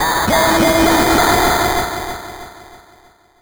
Techno / Voice / VOICEFX228_TEKNO_140_X_SC2(R).wav